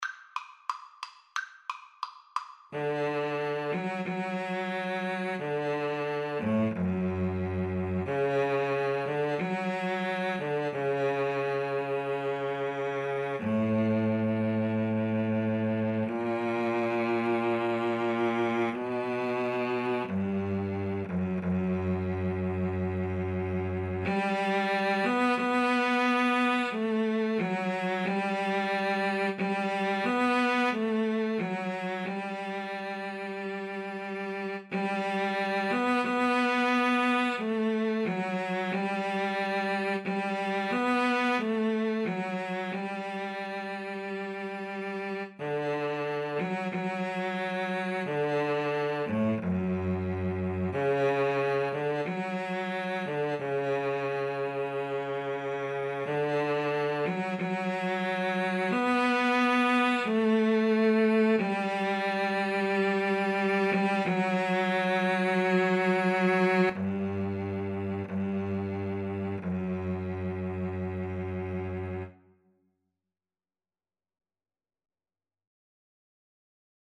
Free Sheet music for Cello Duet
D major (Sounding Pitch) (View more D major Music for Cello Duet )
=180 Largo